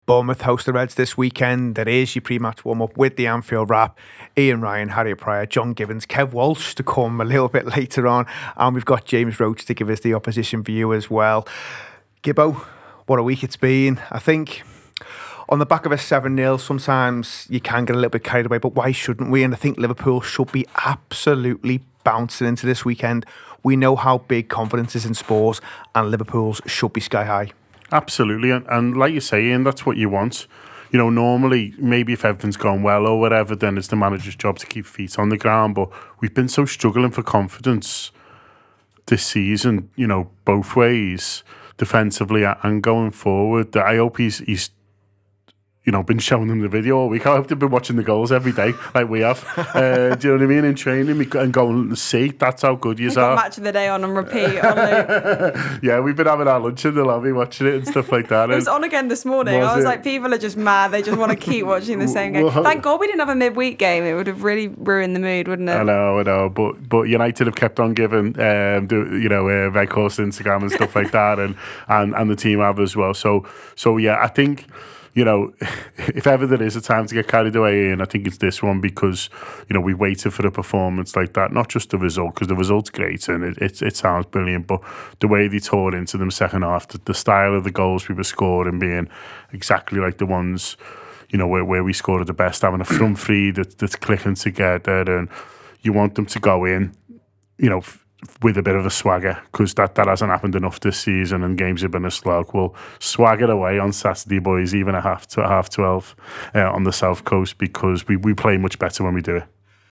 Below is a clip from the show – subscribe for more pre-match build up around Bournemouth v Liverpool…